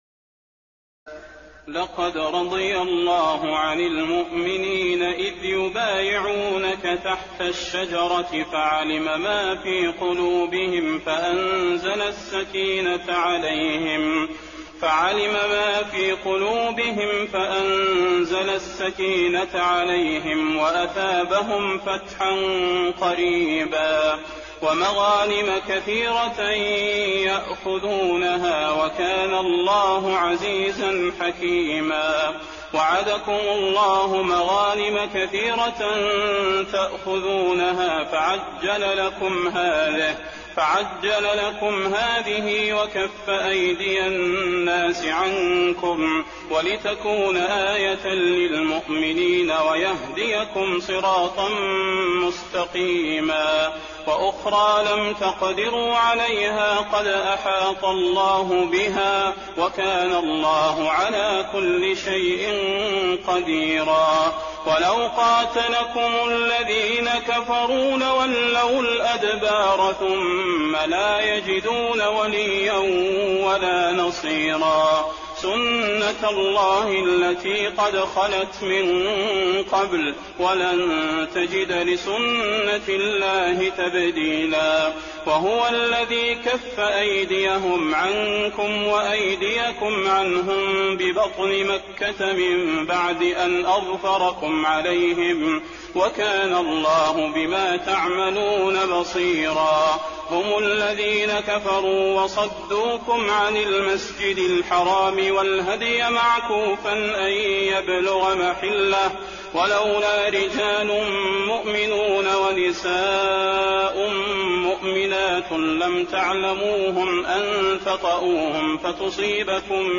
تراويح ليلة 25 رمضان 1422هـ من سور الفتح (18-29) الحجرات و ق و الذاريات (1-23) Taraweeh 25 st night Ramadan 1422H from Surah Al-Fath and Al-Hujuraat and Qaaf and Adh-Dhaariyat > تراويح الحرم النبوي عام 1422 🕌 > التراويح - تلاوات الحرمين